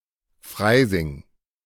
Freising (German pronunciation: [ˈfʁaɪzɪŋ]